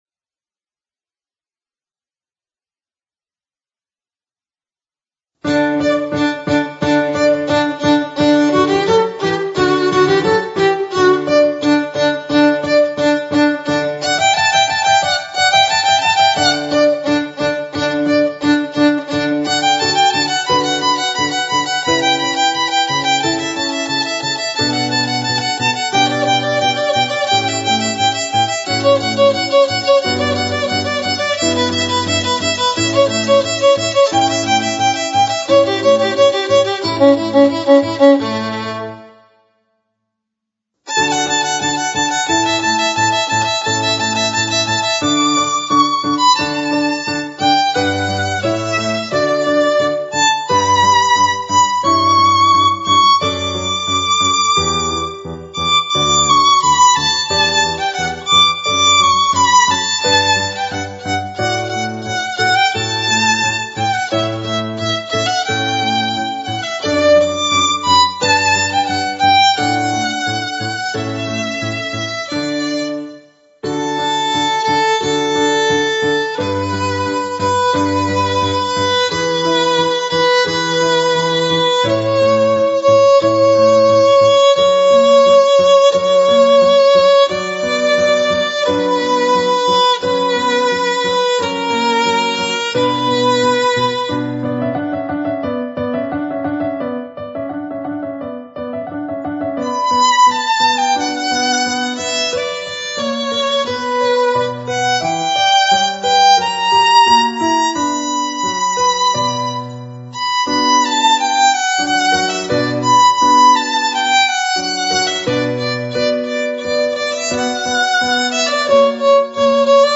Instrumentation: Violin and Piano